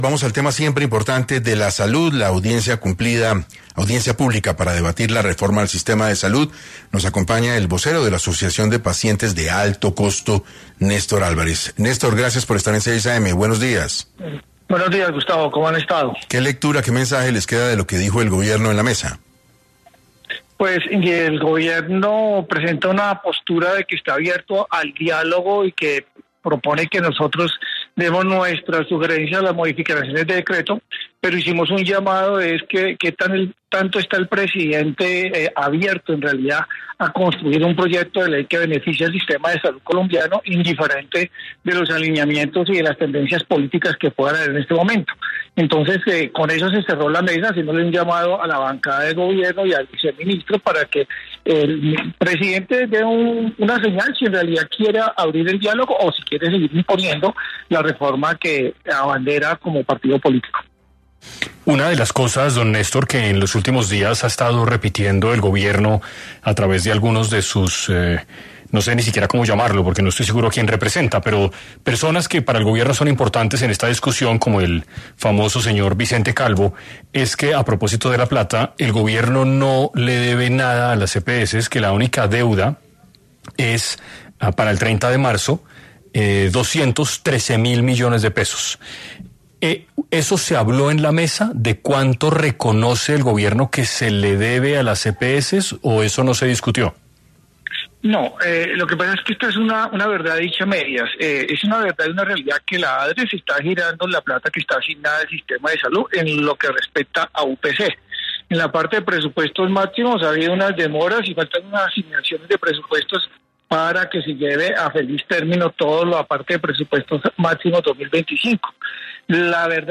Radio en vivo